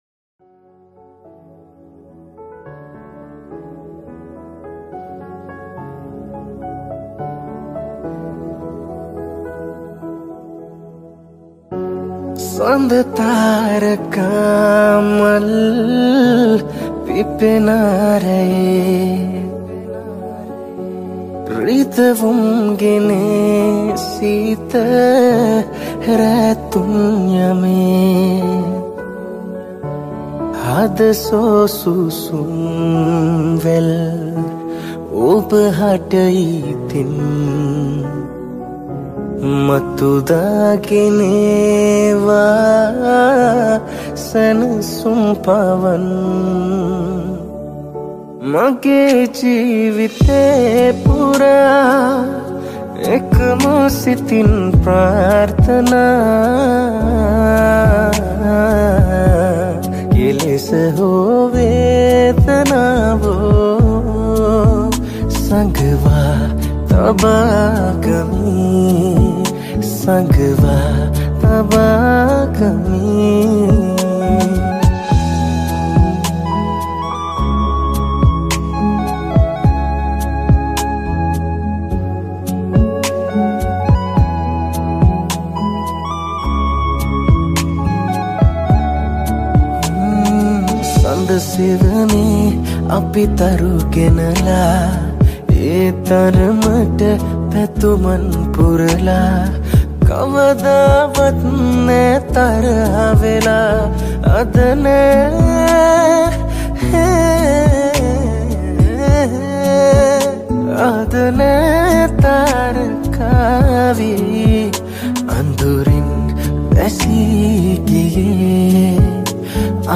Cover Mashup